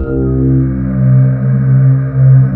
Index of /90_sSampleCDs/USB Soundscan vol.28 - Choir Acoustic & Synth [AKAI] 1CD/Partition D/07-STRATIS